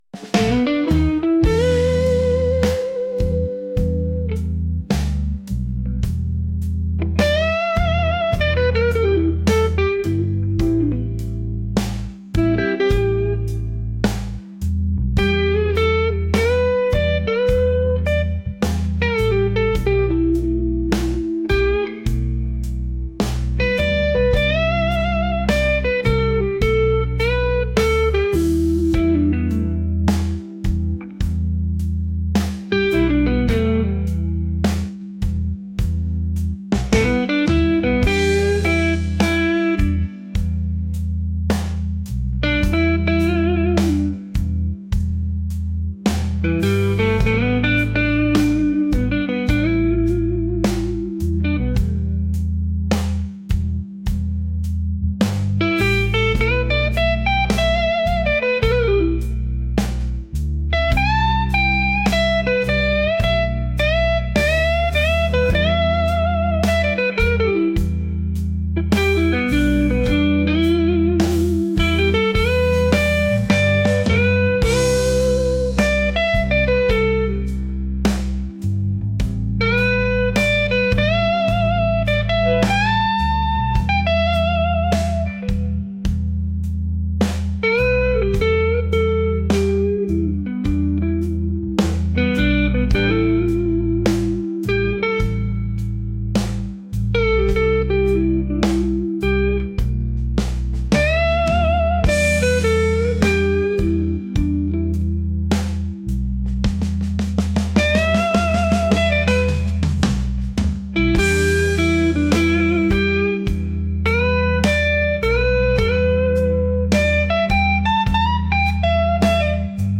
soulful | blues